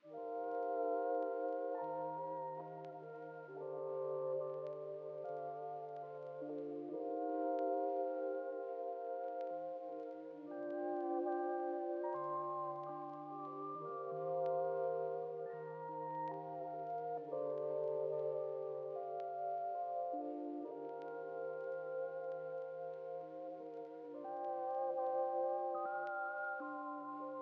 000_ODDMS_Blind_Pad_Loop_70BPM_Gm.wav